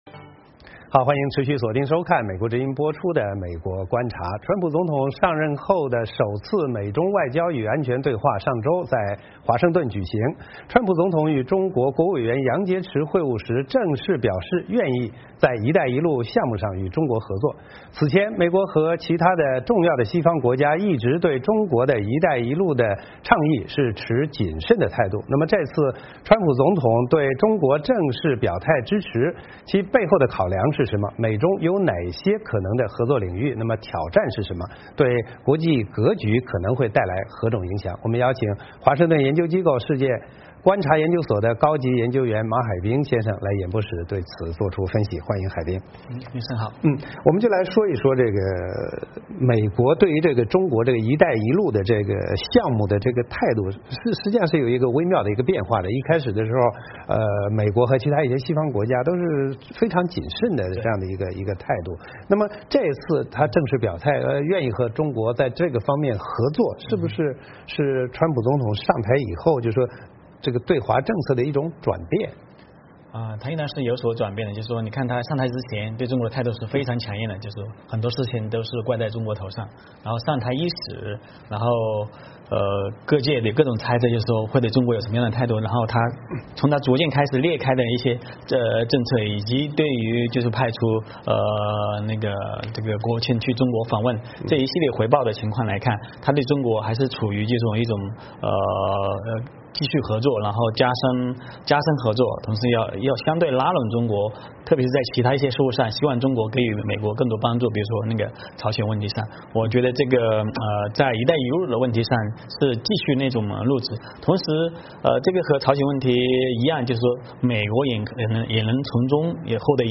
特邀嘉宾